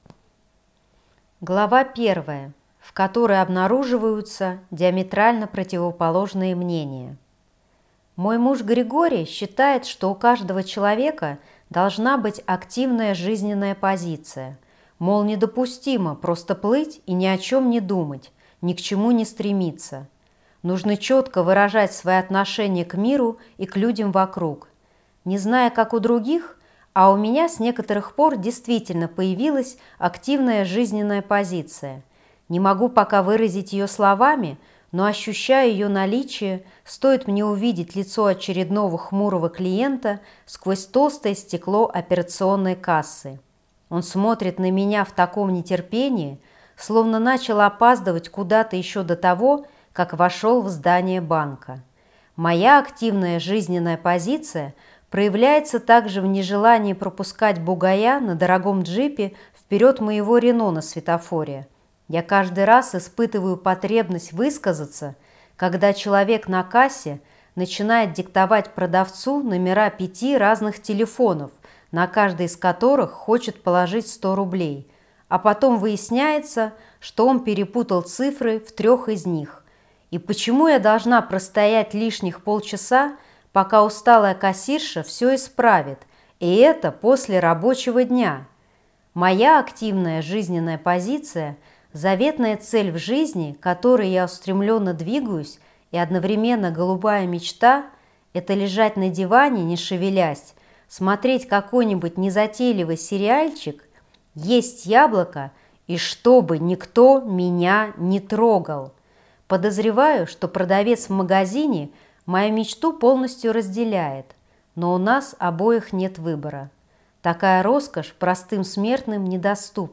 Аудиокнига Как женить слона - купить, скачать и слушать онлайн | КнигоПоиск